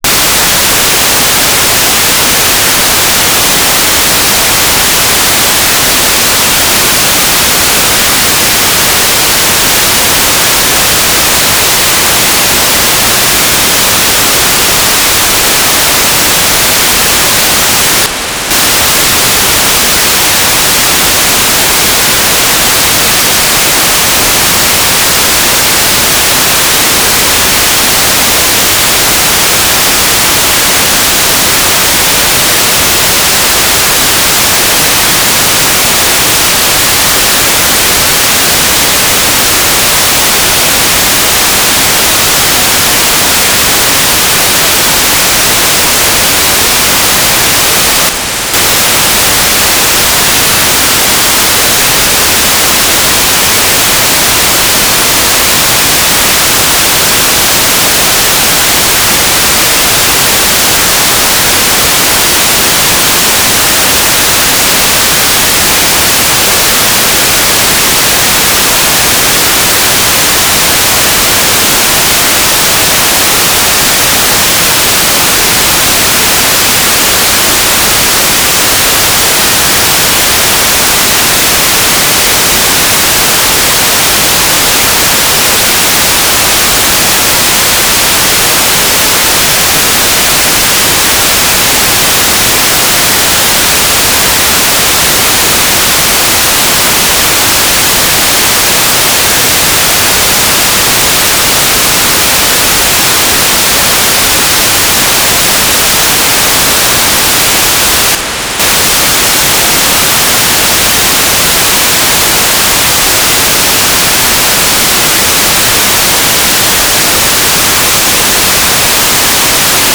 "transmitter_mode": "GMSK USP",